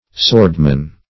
Swordmen - definition of Swordmen - synonyms, pronunciation, spelling from Free Dictionary Search Result for " swordmen" : The Collaborative International Dictionary of English v.0.48: Swordman \Sword"man\, n.; pl. Swordmen .